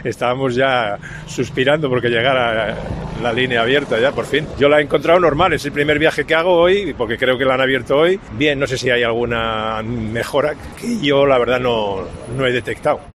usuario habitual de la línea 4, agradece que vuelva a abrirse a los viajeros